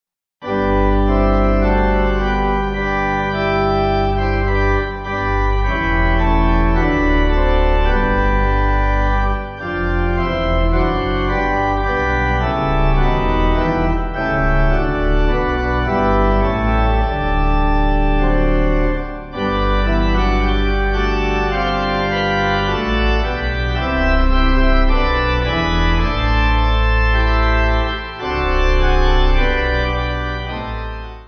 Organ
(CM)   3/G